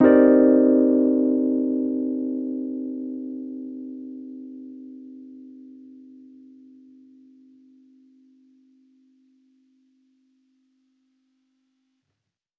Index of /musicradar/jazz-keys-samples/Chord Hits/Electric Piano 1
JK_ElPiano1_Chord-Cm9.wav